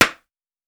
Foley Sports / Hockey / Ball Contact Mask.wav
Ball Contact Mask.wav